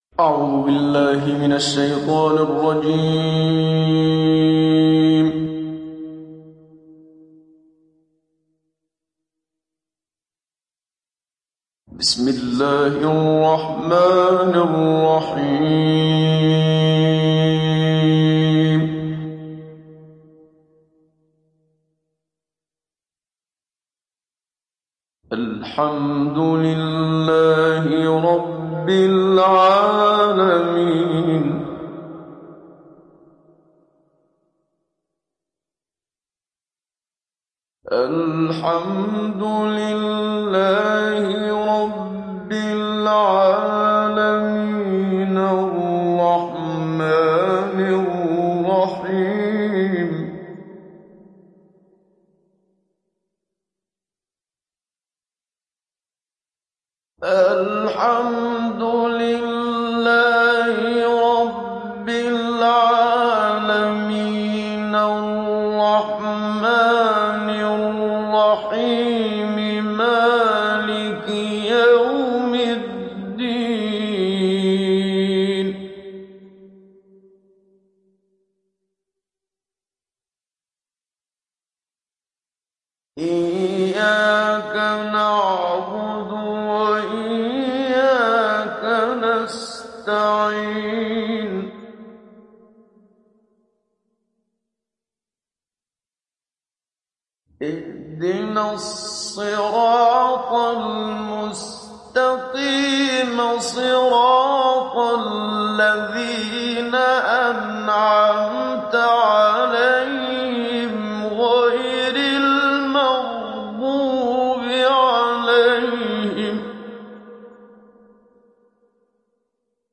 Download Surah Al Fatihah Muhammad Siddiq Minshawi Mujawwad